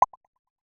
Simple Cute Alert 15.wav